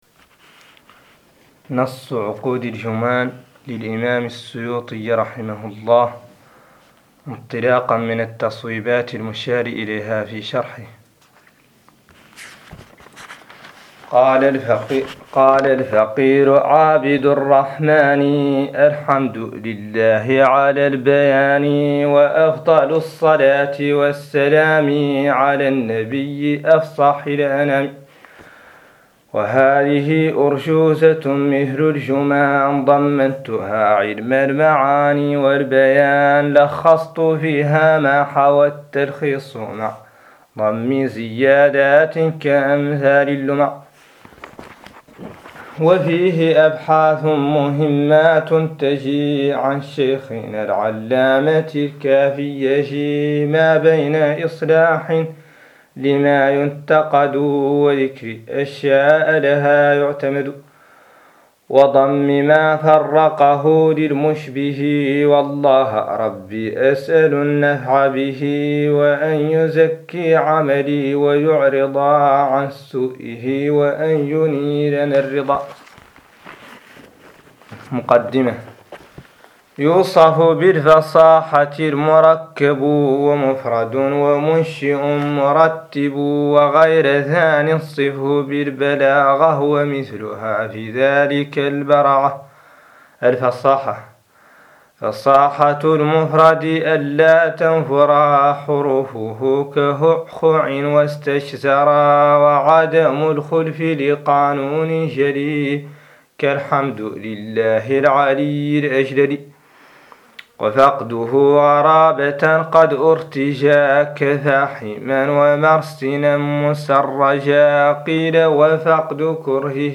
قراءة نظم عقود الجمان للسيوطي 01